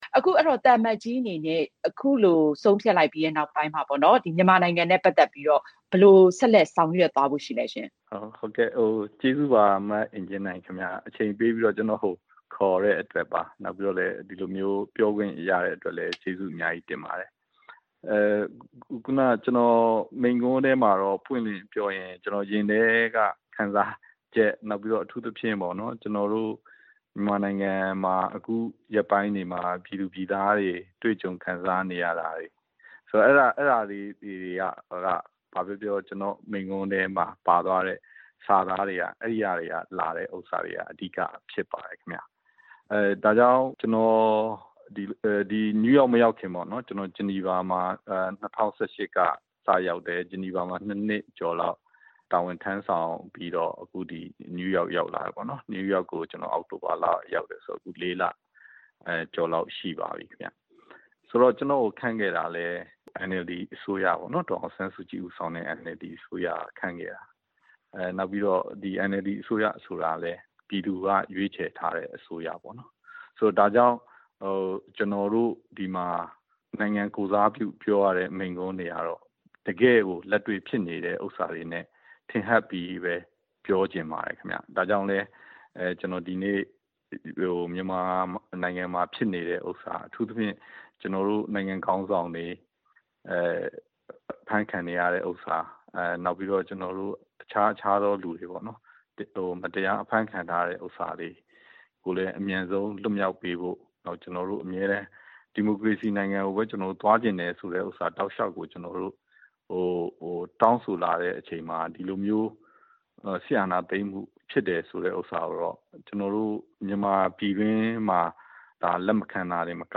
ကုလဆိုင်ရာ မြန်မာအမြဲတမ်းကိုယ်စားလှယ် သံအမတ်ဦးကျော်မိုးထွန်းနဲ့ ဆက်သွယ်မေးမြန်းချက်။